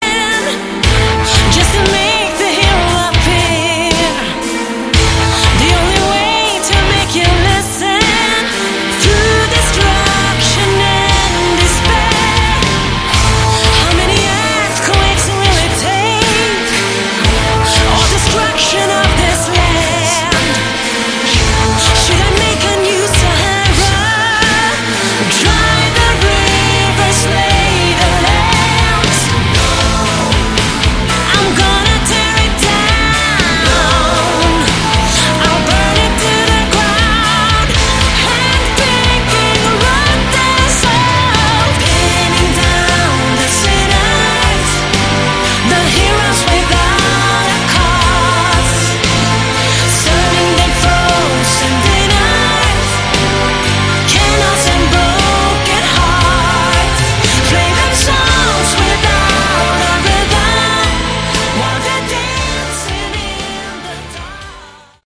Category: Melodic Synmphonic Prog Metal
lead and background vocals
drums, keyboards
guitars
bass guitar